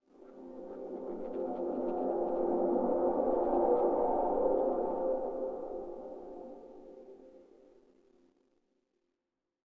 WAV · 416 KB · 單聲道 (1ch)